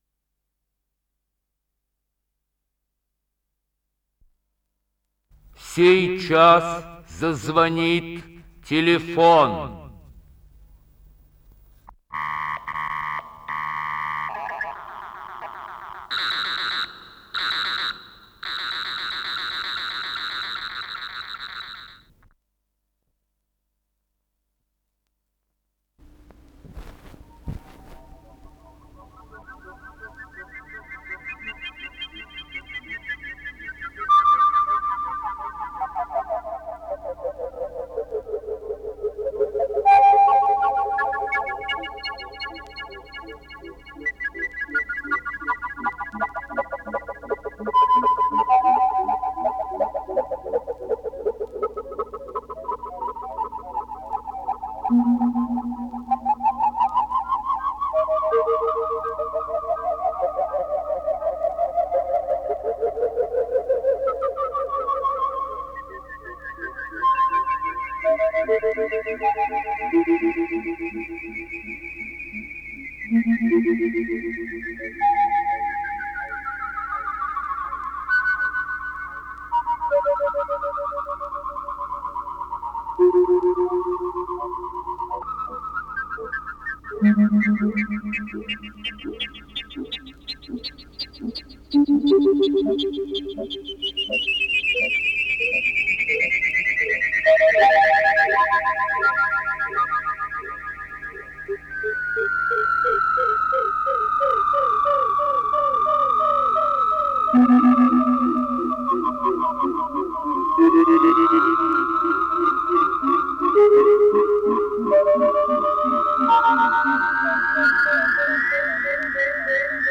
Н-778 — Электронные шумы — Ретро-архив Аудио